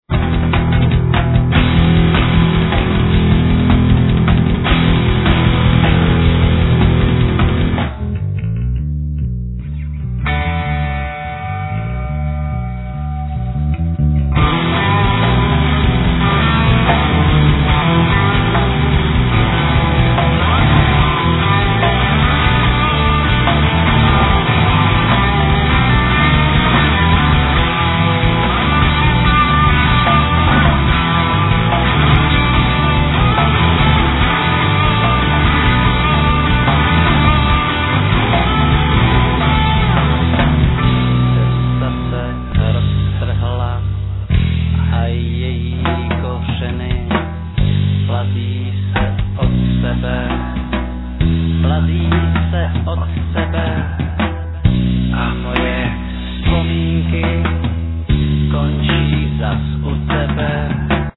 Guitars, Vocals, Percussions, Handclap, Echoes
Bass, Vocals, Acoustic guitar, Piano, Percussions
Drum kit, Vocals, Paino, Percussions, Handclap
Piano, Organ, Percussions, Snap